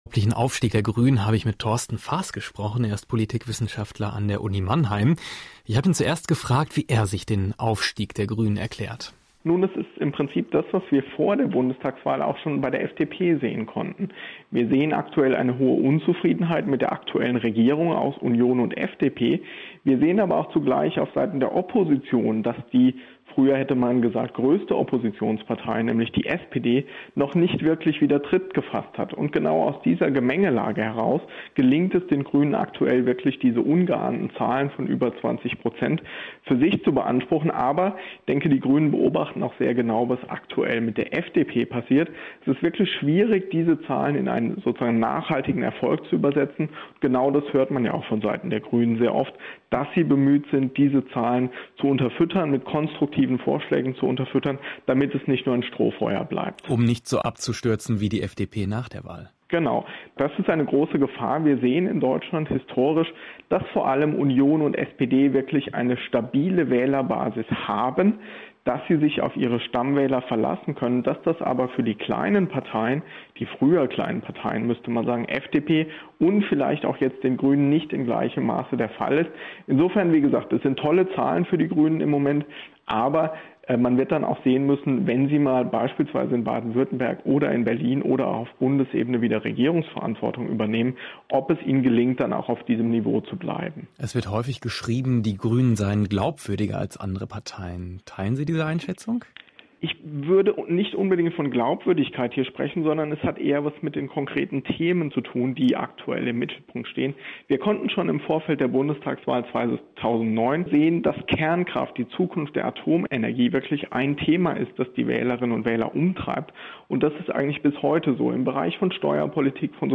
Über den derzeitigen Höhenflug der Grünen in den Umfragen habe ich gestern mit der Deutschen Welle gesprochen. Das komplette Interview gibt es hier als MP3.